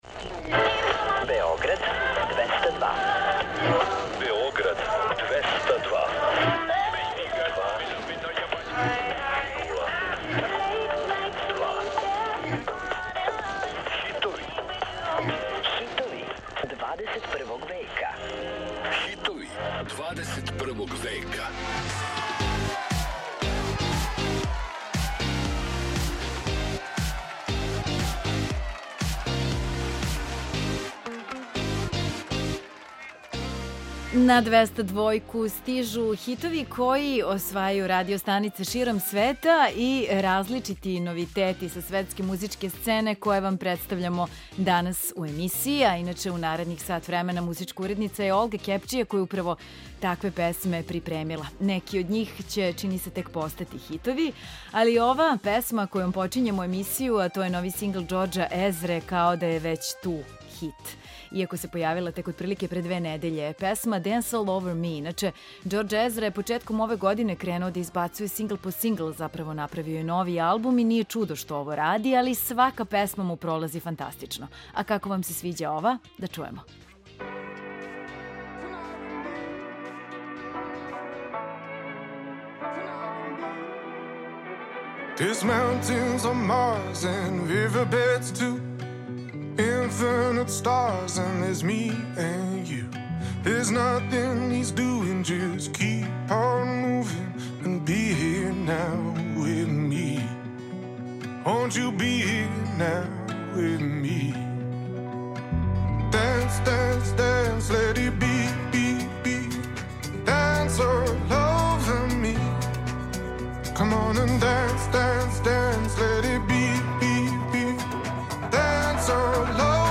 Чућете песме које се налазе на врховима светских топ листа.